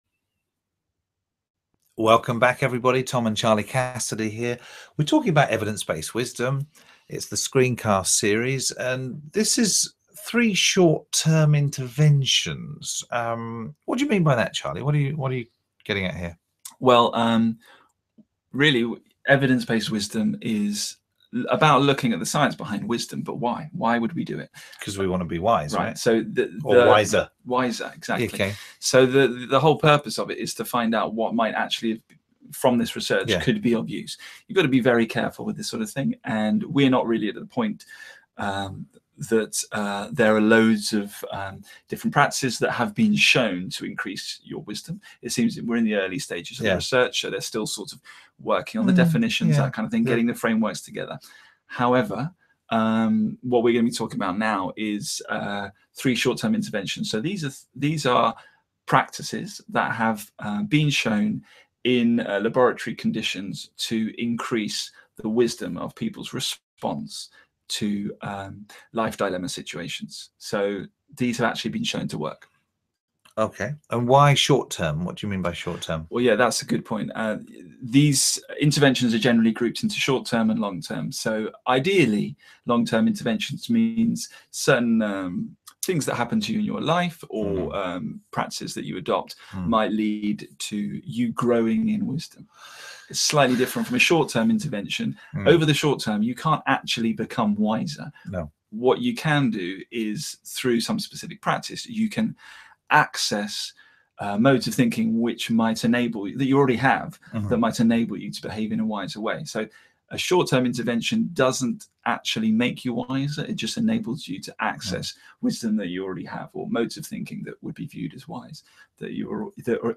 The Evidence-based Wisdom Screencast Series is a collection of short conversations discussing 10 of the major ideas and themes emerging from the field of Wisdom Research.